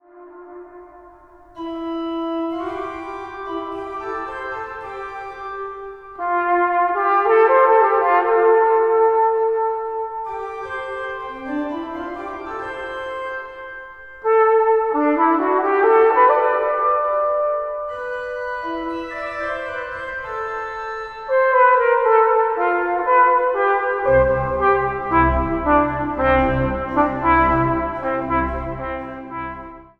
Populaire klanken op orgel & trompet
trompet, piccolo trompet, bügel & melodica.
Instrumentaal | Trompet